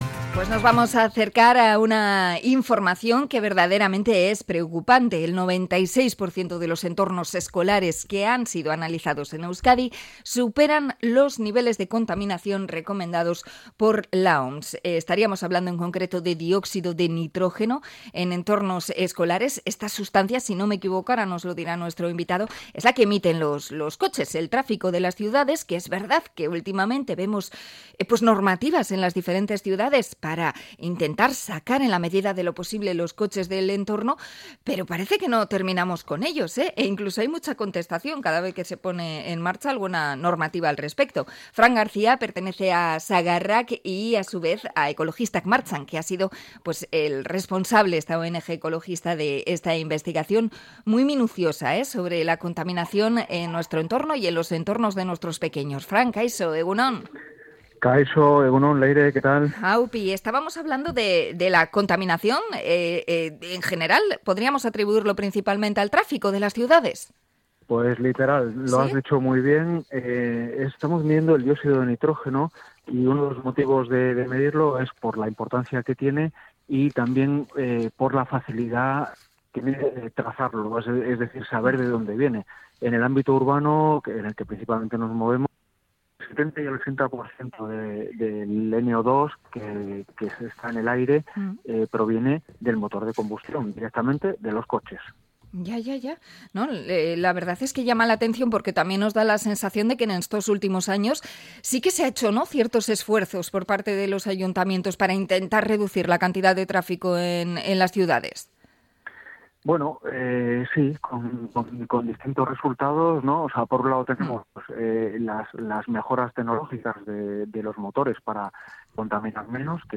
Entrevista a Sagarrak por el infirme sobre la contaminación del tráfico en las escuelas